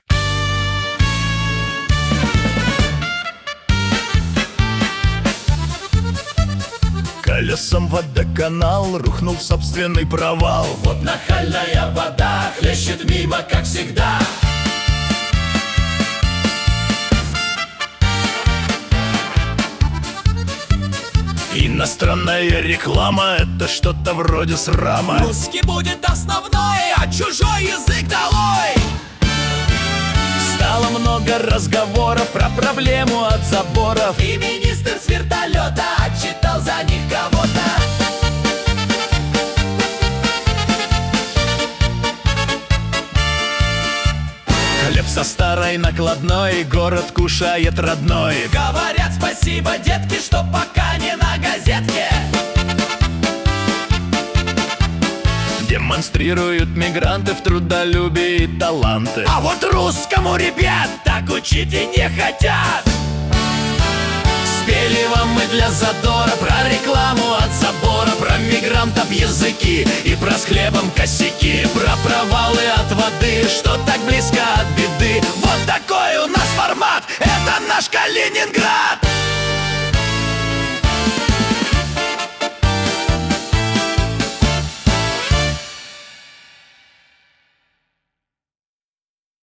«Колесом «Водоканал» рухнул в собственный провал»: музыкальный дайджест калининградских новостей за неделю (видео)
Куплеты на злобу дня о главных и важных событиях